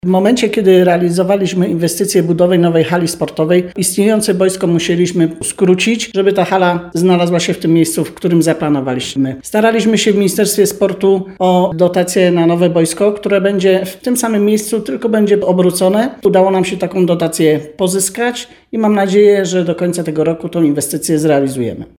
Mówi o tym Dorota Nikiel z zarządu Powiatu Bielskiego, który prowadzi szkołę.